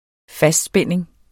Udtale [ ˈfasdˌsbεnˀeŋ ]